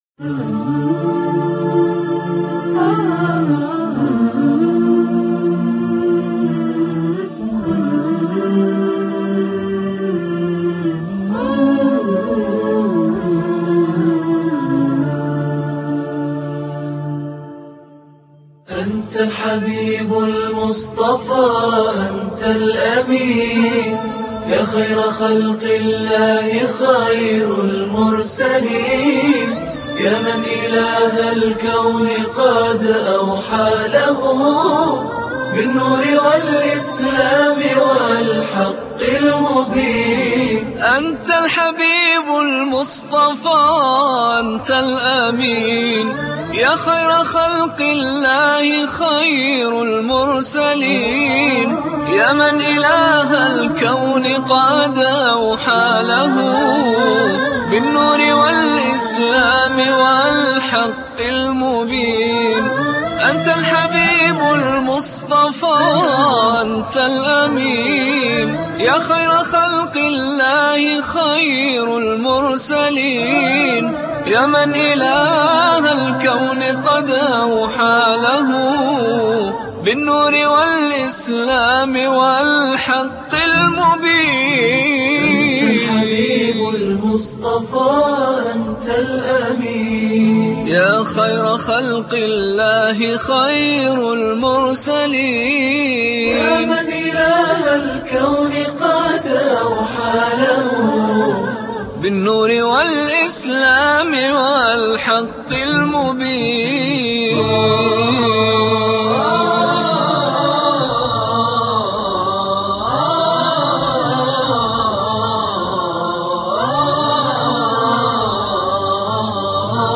دانلود تواشیح